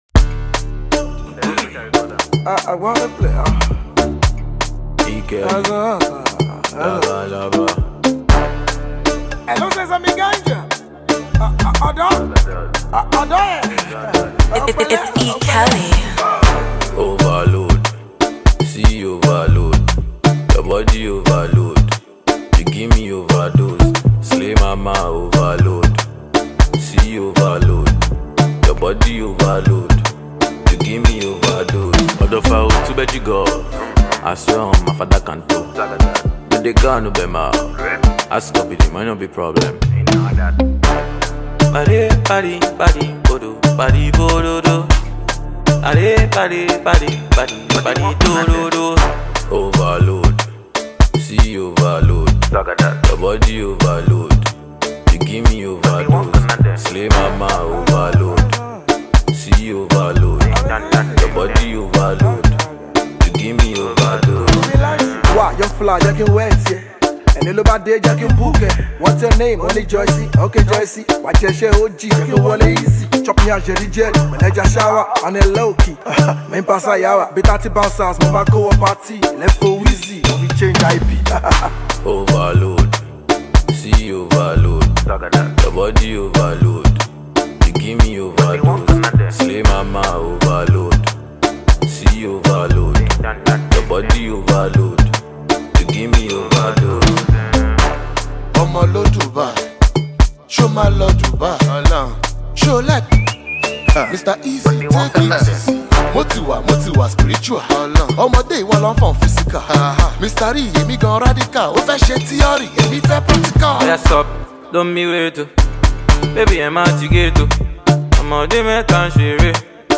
Shaku Shaku vibes